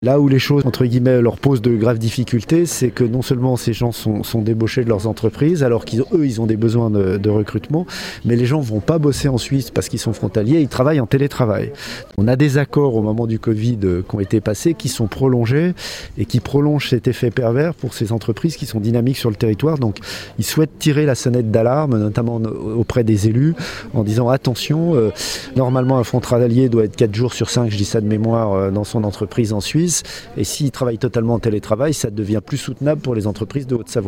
Dominique Puthod, conseiller départemental de la Haute-Savoie et Président de la CITIA revient sur ce phénomène de concurrence avec la Suisse qui s'est aggravé avec la crise : Télécharger le podcast Partager :